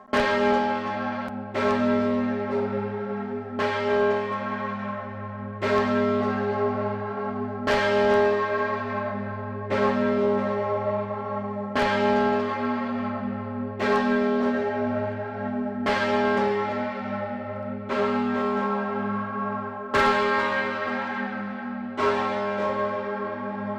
Mit 5.026 kg und einem Durchmesser von 201 cm ist die im Ton as/0 gestimmte Augustinusglocke (auch Kaiserglocke genannt) die zweitgrößte Kirchenglocke Südtirols. Sie wurde im Jahr 1895 von Carlo Chiappani in Trient gegossen. Am 14. August 2014 hatte ich die einmalige Gelegenheit, das Geläute der Stiftskirche von Muri-Gries (Bozen) direkt im Turm zu hören.